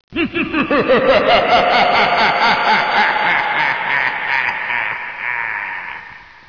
evillaugh.wav